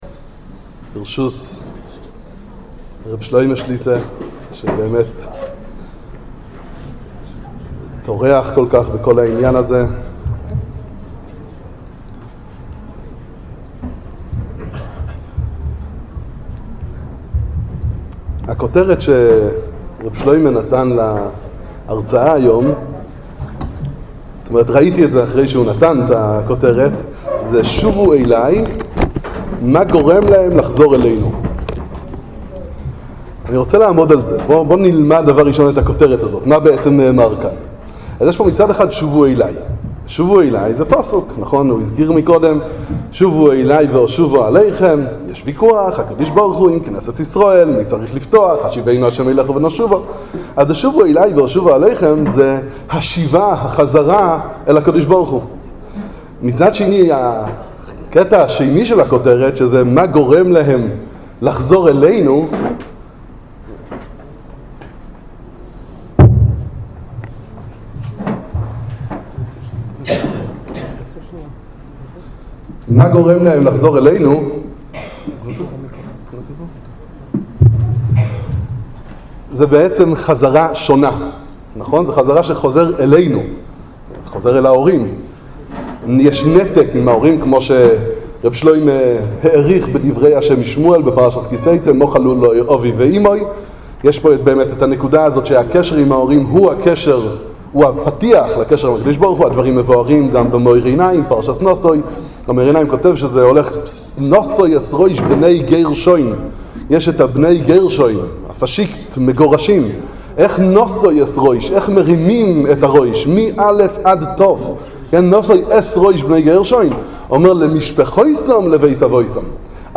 הרצאה להורים